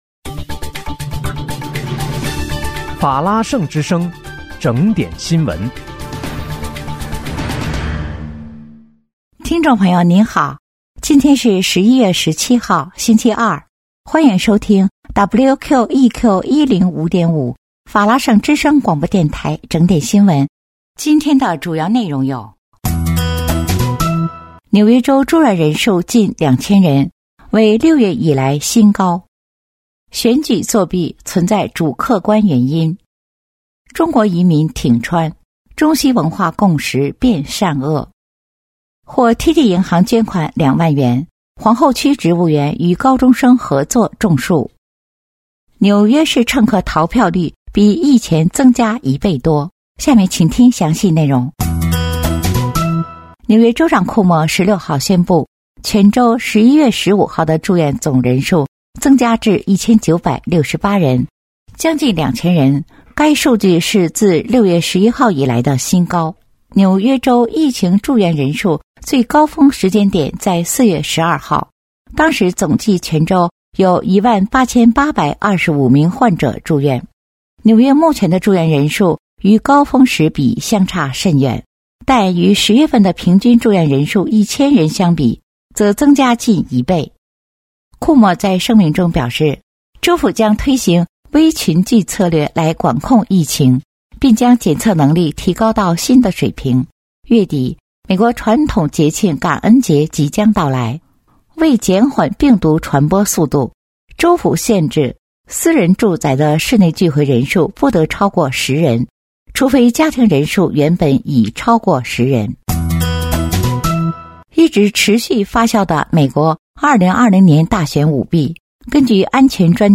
11月17日（星期二）纽约整点新闻